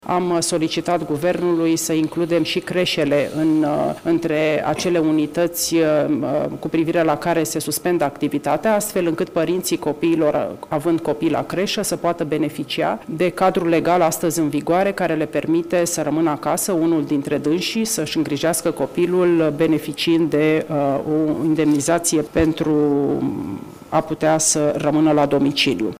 Ministrul Muncii, Violeta Alexandru: